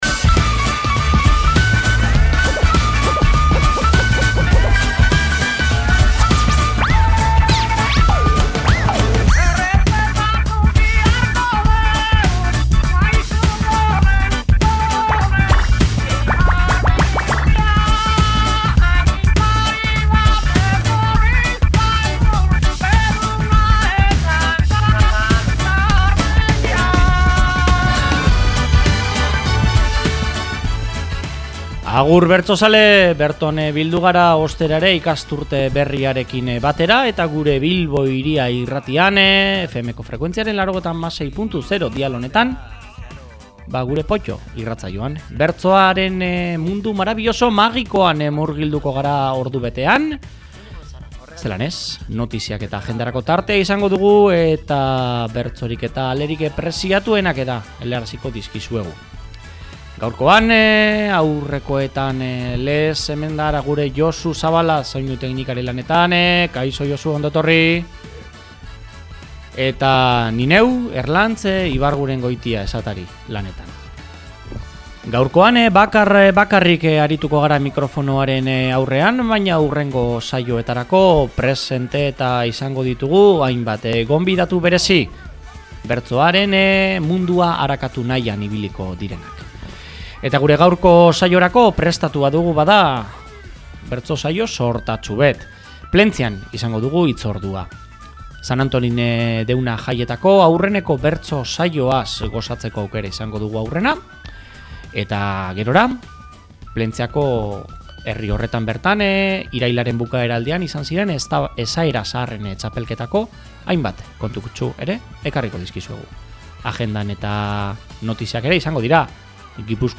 * Irailaren 1ean, Antolin deuna jaien harira antolatutako bertso-jaialdia.
* Irailaren 26an, Plentziako Esaera Zaharren txapelketa Ez dugu esandakoaren inolako damurik!